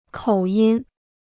口音 (kǒu yin) Kostenloses Chinesisch-Wörterbuch